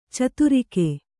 ♪ caturrike